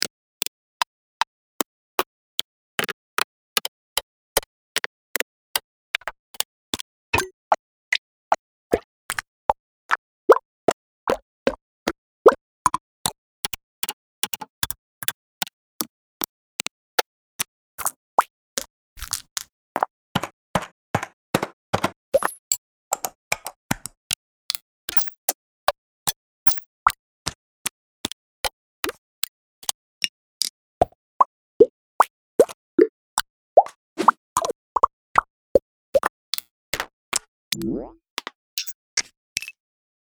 FueraDeEscala/Assets/Free UI Click Sound Effects Pack/Sound Effects Review.mp3
Sound Effects Review.mp3